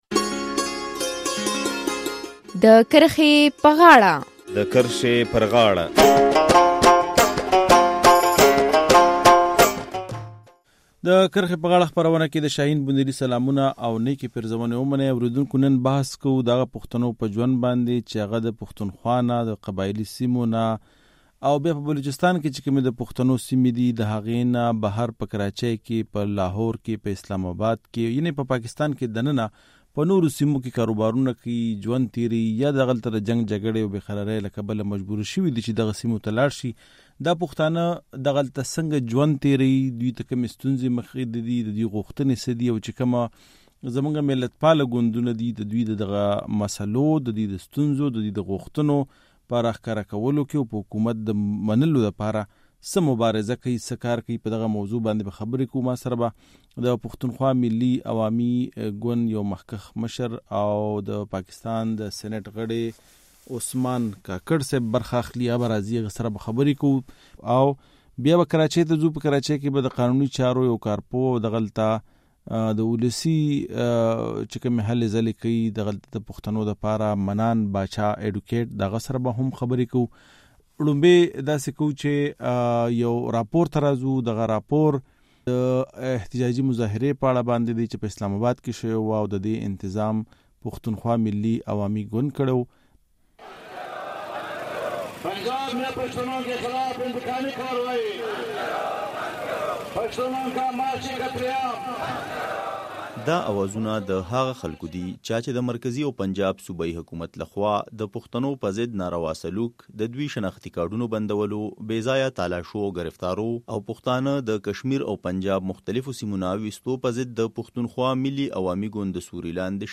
د کرښې پر غاړه خپرونه کې په دې موضوع بحث کوو، چې کوم پښتانه د پاکستان په نورو صوبو کې ژوند کوي هغوي له کومو ستونزو سره مخامخ دي. هغوي له حکومته څه غوښتنې لري او ملت پاله ګوندونه د هغوي په ستونزو هوارولو کې څه کردار لوبوي؟